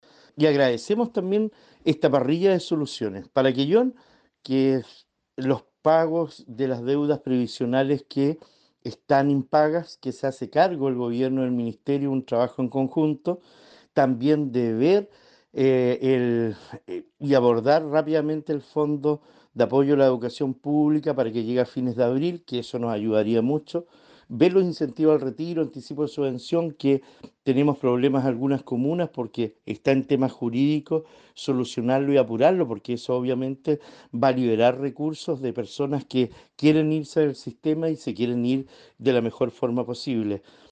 El alcalde de Quellón, Cristian Ojeda, se refirió a las soluciones que para la comuna se pudieron obtener en la jornada de conversaciones con el ministro del ramo, lo que apunta a recibir el adelanto del FAEP y del Bono de Incentivo al Retiro.